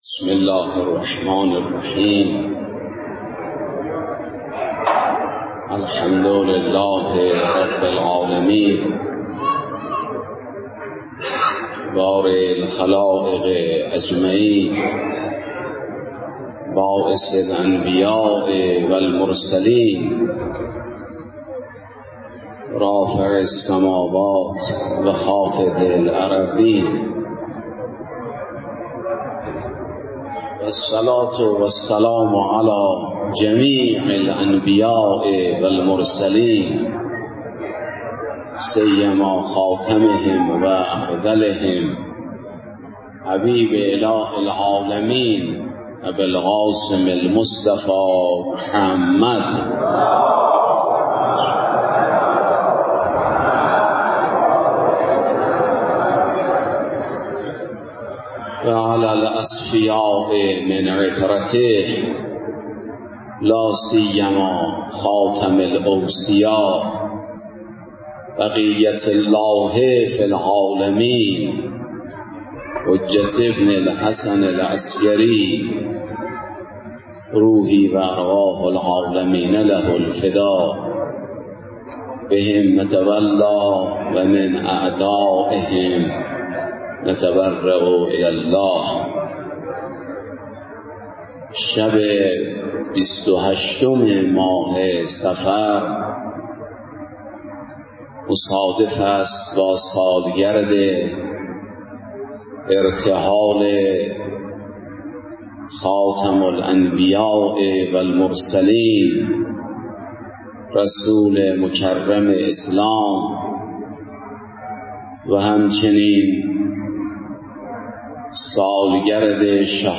در مراسم دهه آخر صفر (جلسه سوم)
مراسم عزاداری ایام آخر ماه صفر